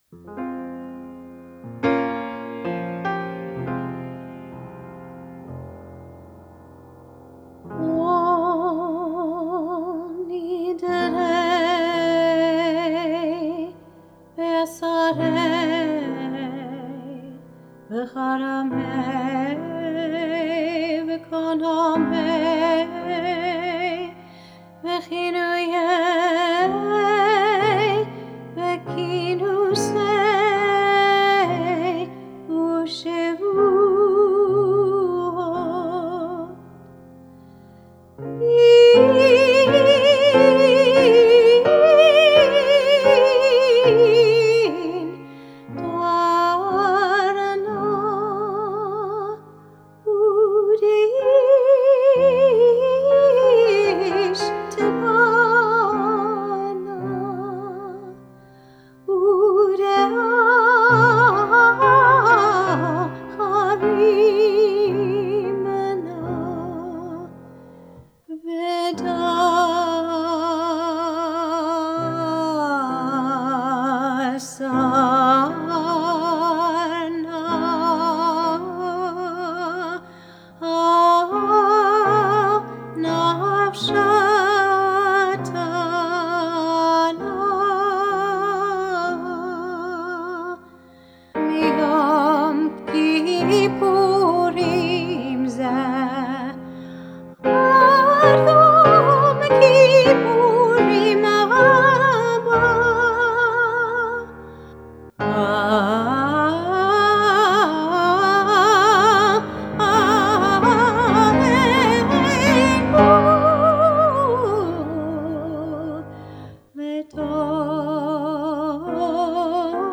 Her voice has been characterized as very versatile and her range spans accessible keys so that the congregation can easily join with her in song and worship to more contemplative recitatives that allow people to sit back and take in the music.